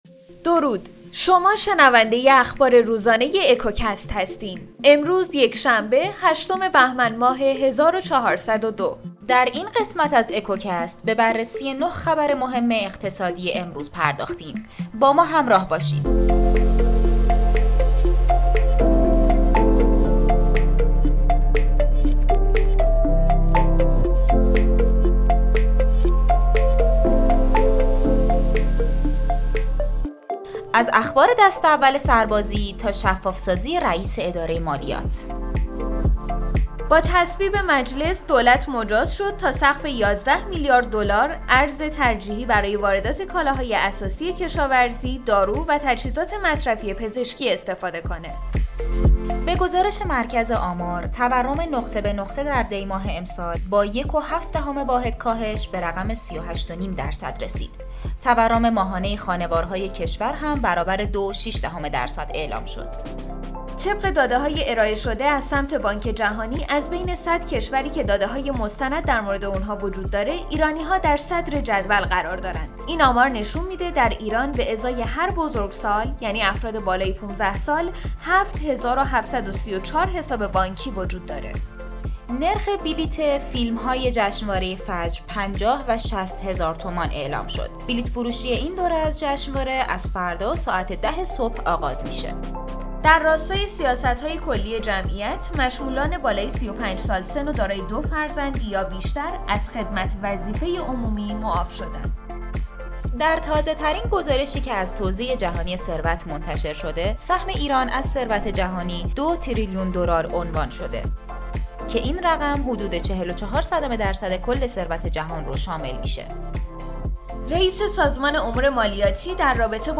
به گزارش ایران اکونومیست؛ اکوکست؛ صدایی‌ رسا برای اقتصاد ایران، هر روز ساعت ۱۸:۰۰ منتظر خلاصه‌ای از مهمترین و اثرگذارترین اخبار اقتصادی روز باشید.